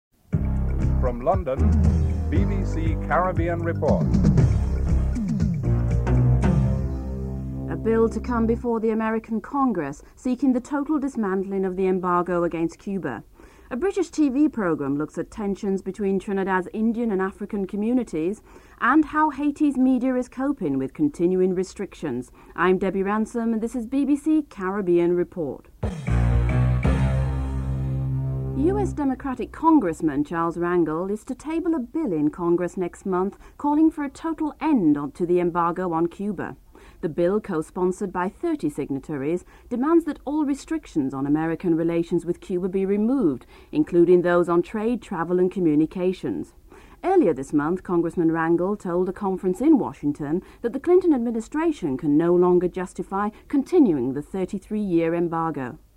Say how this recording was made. The British Broadcasting Corporation